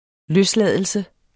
Udtale [ -ˌlæˀðəlsə ]